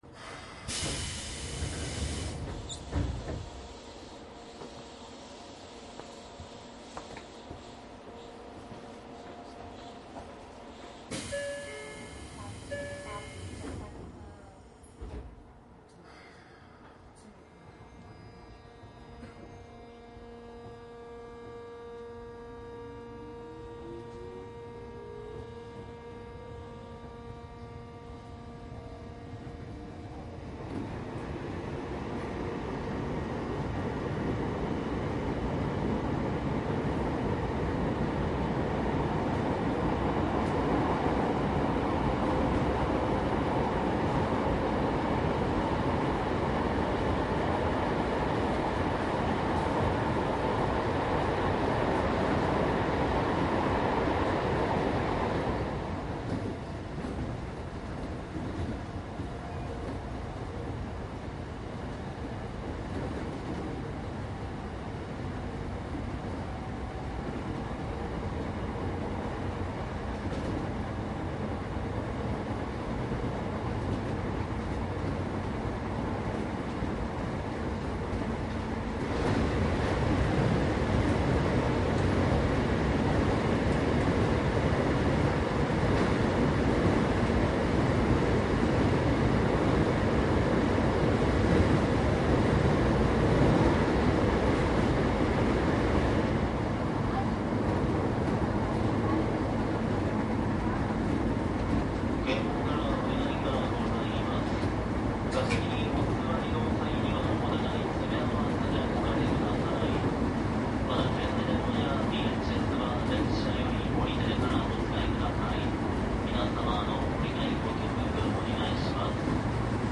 京王９０００系 ・10－000系 都営新宿線 走行音
地下鉄線内京王9000系の急行運転と各駅停車の都営10系の録音。
■【急行】橋本→笹塚 10－251＜DATE01－5－3＞
マスター音源はデジタル44.1kHz16ビット（マイクＥＣＭ959）で、これを編集ソフトでＣＤに焼いたものです。